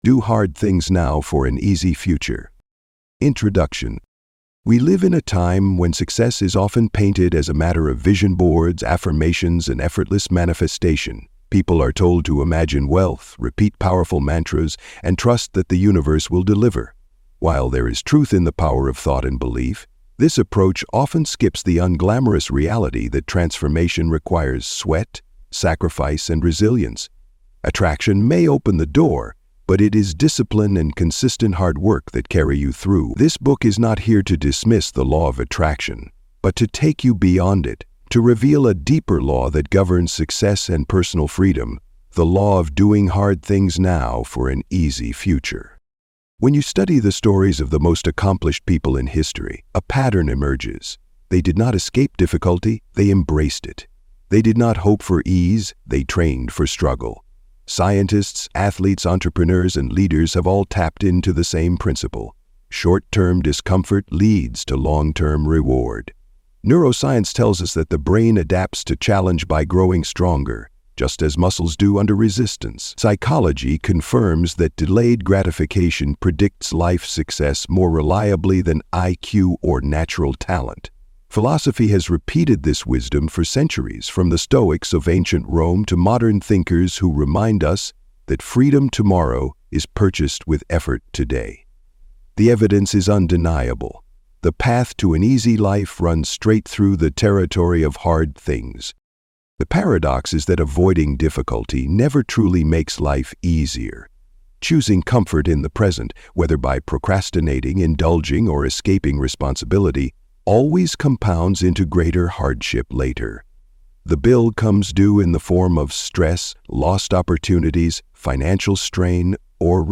The Art of Inner Calm: Master Your Reactions (Audiobook)